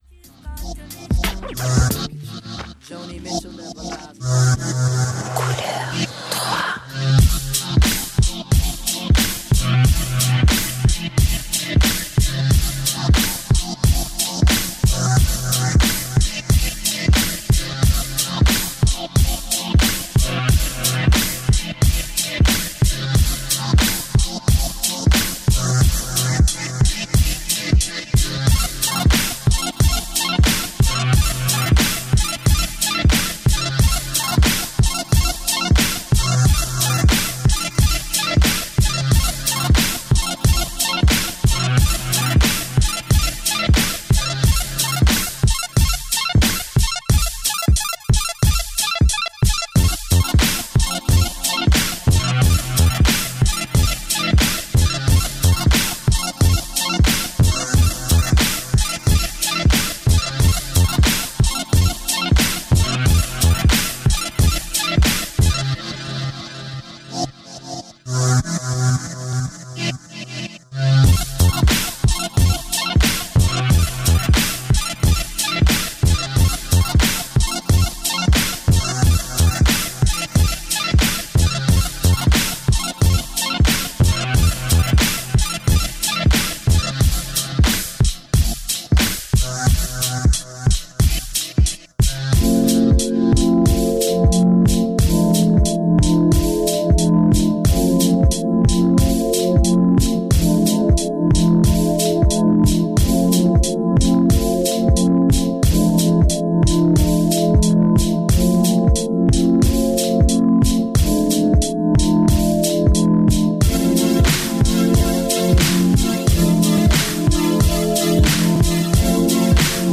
Een versie zonder tekst, al in 2019 opgenomen: